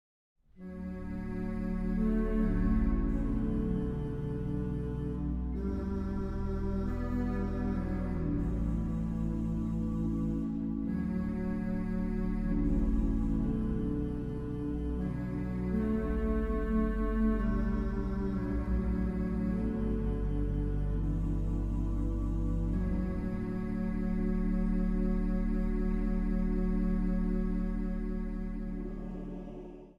orgel
hobo
koorsolisten.
Zang | Mannenkoor